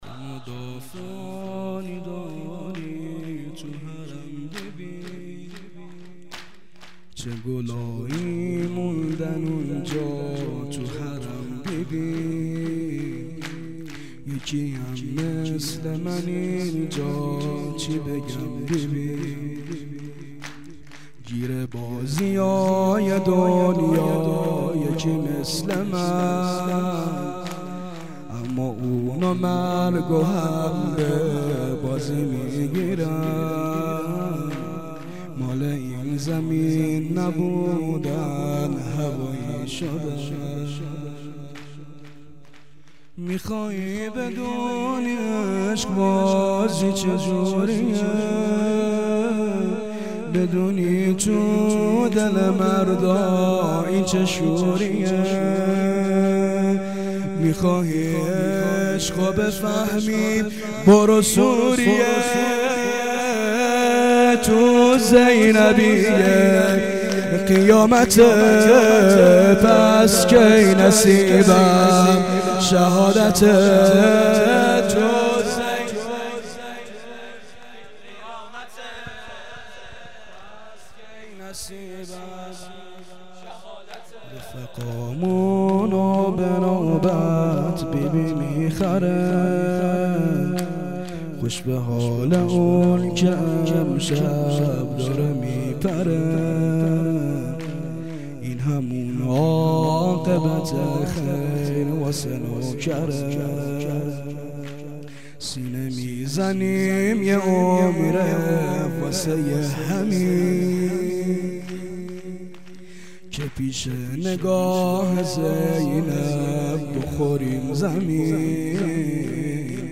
شور پایانی